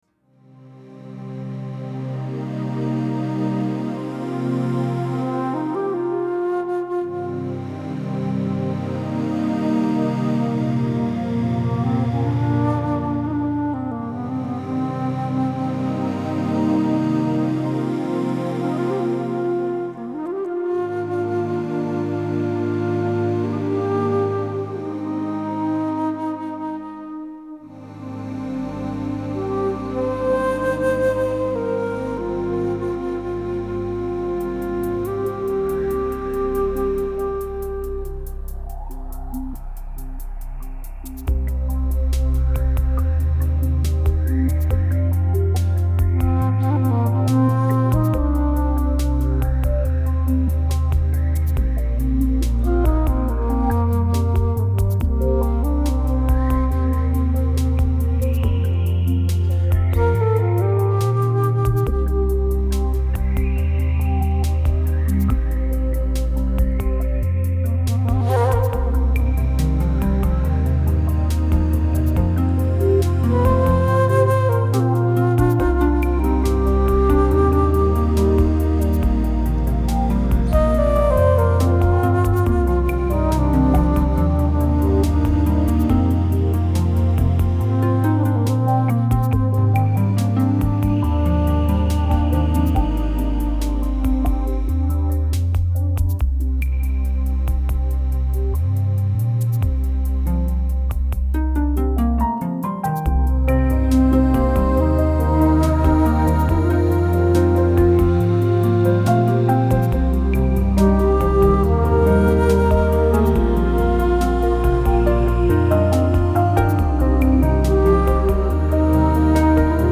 音乐流派；新世纪音乐 (New Age) / 环境音乐 (Ambient Music)
歌曲风格：轻音乐 (Easy Listening) / 纯音乐 (Pure Music)